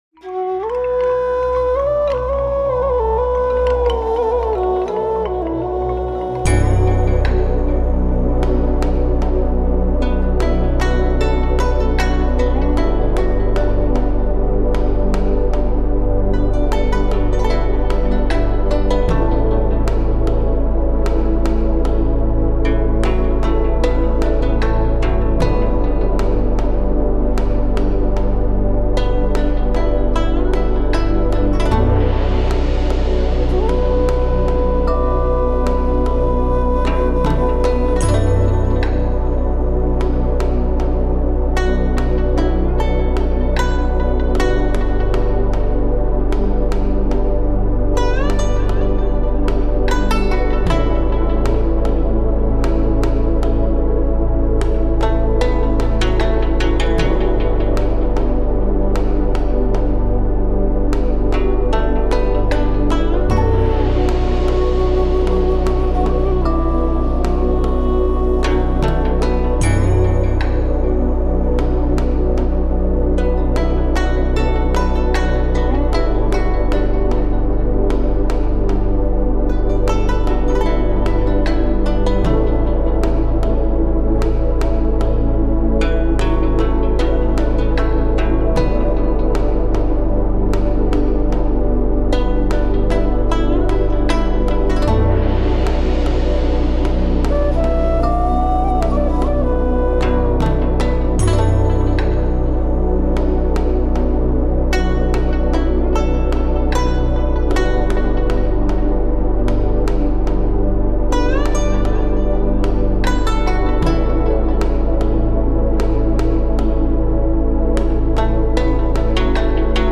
La fréquence 319.9 Hz détoxifice le systeme rénale
La-frequence-319.9-Hz-detoxifice-le-systeme-renale.mp3